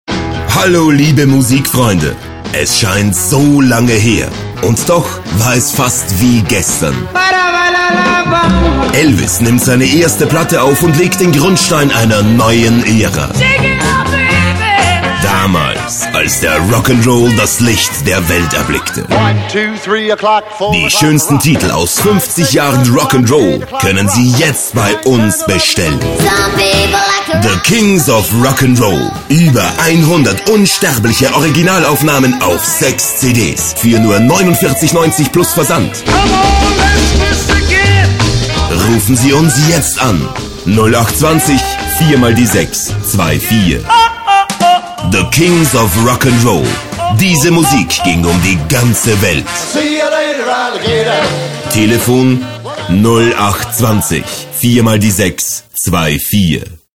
Profi-Sprecher deutsch.
Sprechprobe: eLearning (Muttersprache):